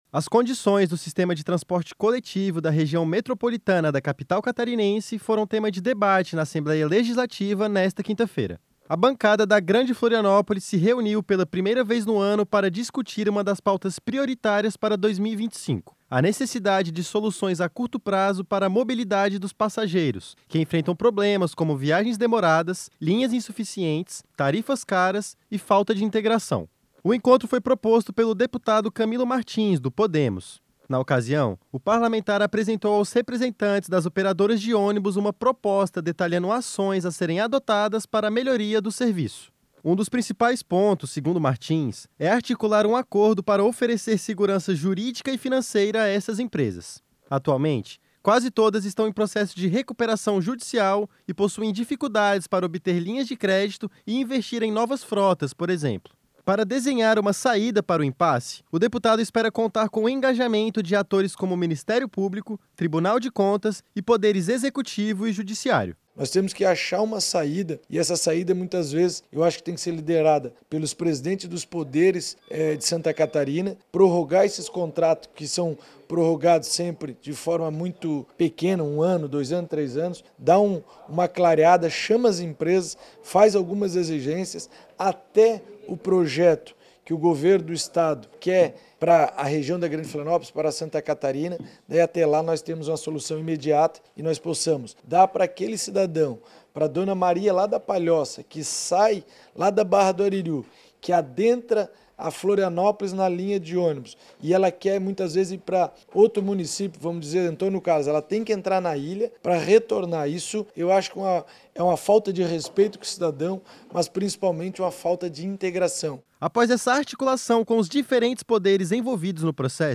Entrevista com:
- deputado Camilo Martins (Podemos);